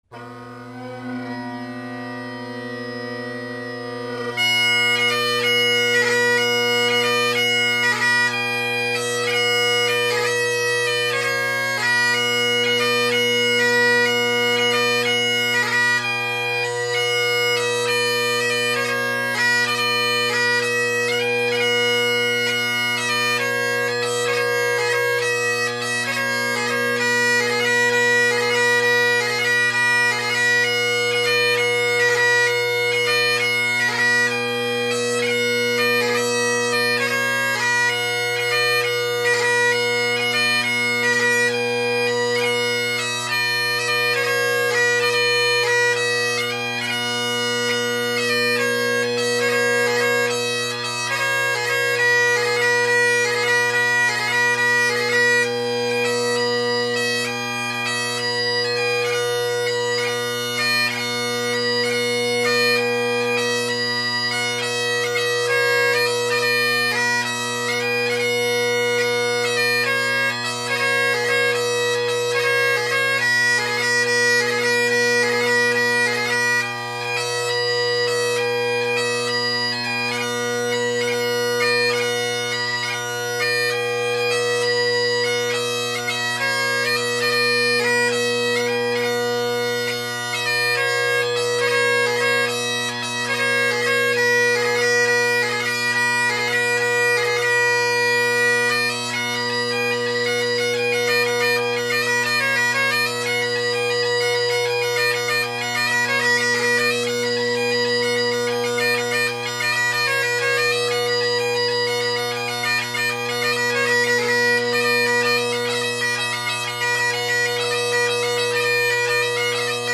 Solid reeds, maybe a bit tenor mellow. The fundamental tone is weaker than most other tenor reeds but the overtones are stronger, making for a unique combination.
a clip with the mic drone side from my full review post here.